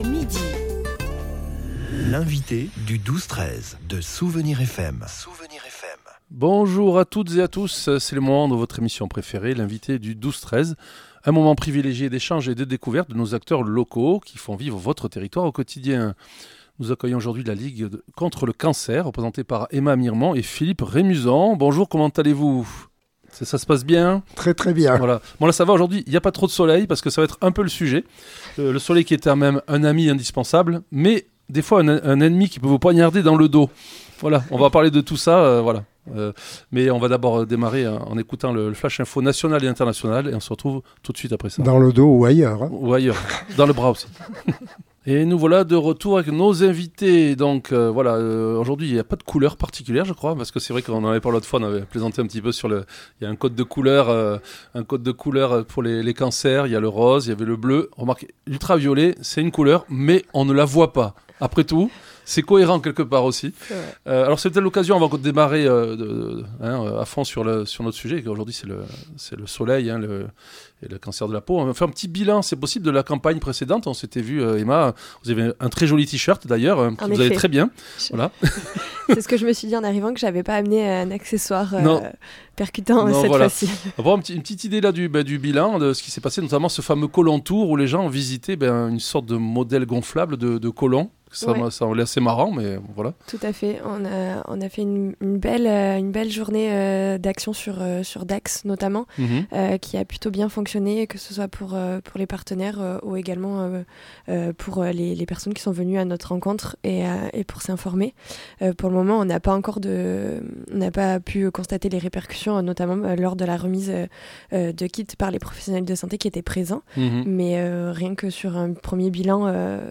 L'invité(e) du 12-13 de Soustons recevait aujourd'hui La ligue contre le cancer.